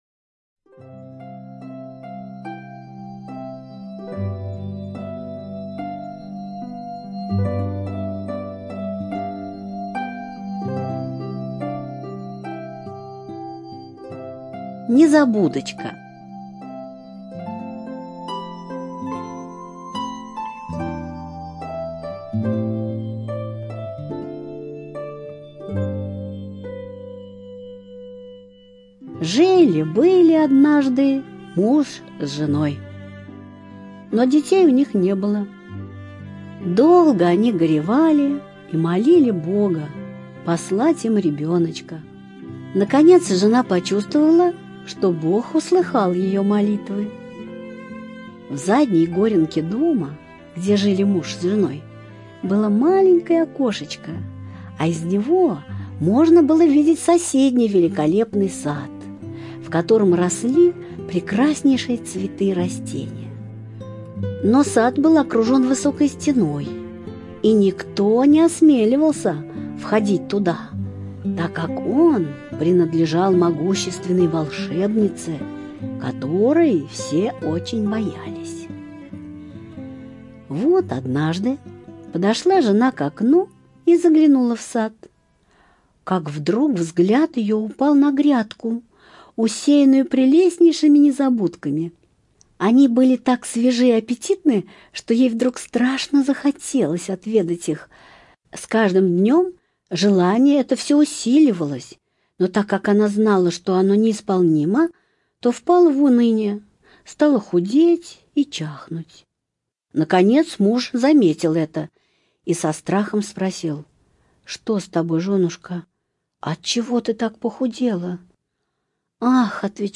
Незабудочка - аудиосказка братьев Гримм. Жили муж с женой.